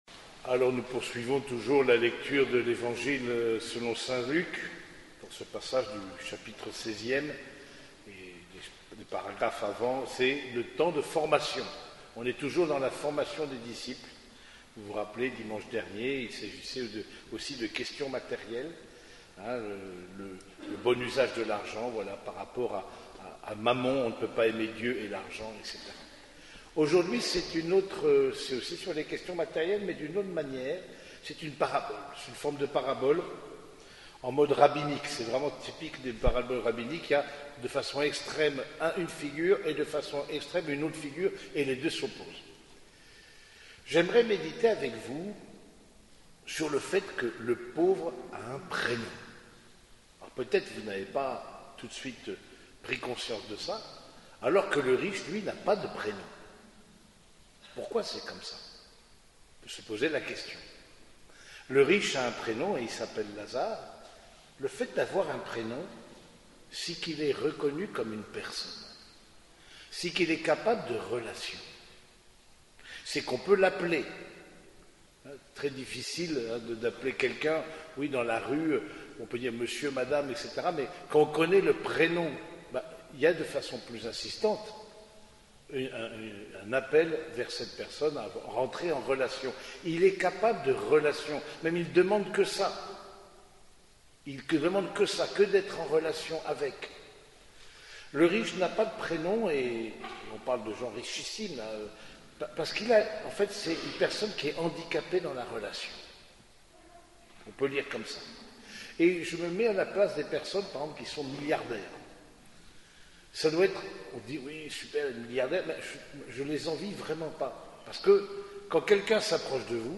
Homélie du 26e dimanche du Temps Ordinaire
Cette homélie a été prononcée au cours de la messe dominicale célébrée à l’église Saint-Germain de Compiègne.